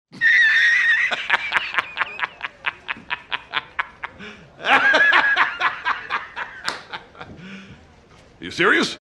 PLAY j jonah jameson laughing meme
j-jonah-laughing.mp3